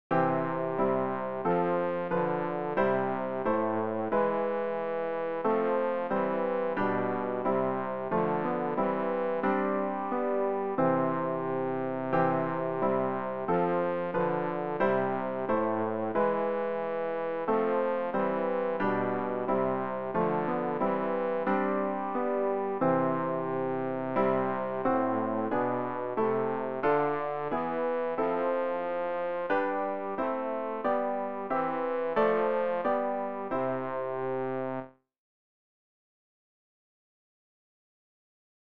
Übehilfen für das Erlernen von Liedern
rg-478-jesus-meine-zuversicht-bass.mp3